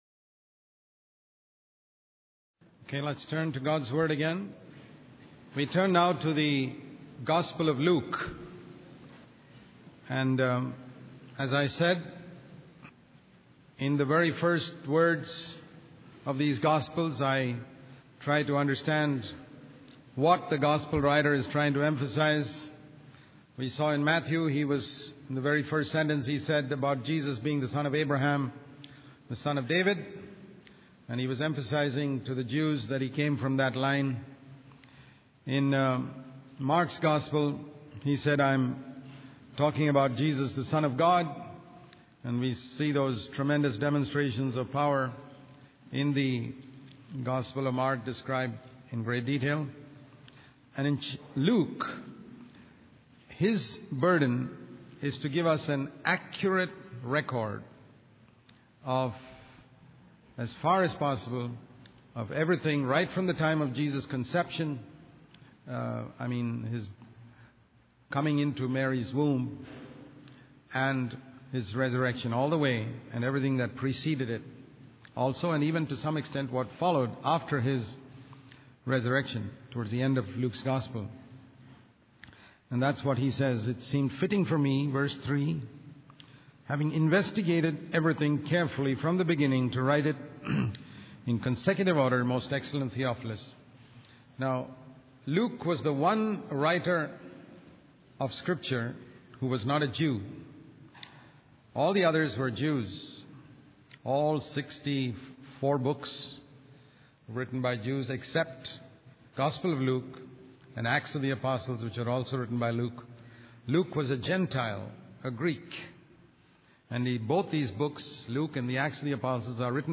In this sermon, the preacher focuses on the fifth chapter of the Bible, specifically verses 4 and 16. The sermon emphasizes the importance of waiting for God's guidance before taking action. It highlights the story of Simon, who initially doubted Jesus' instructions but eventually obeyed and experienced a miraculous catch of fish.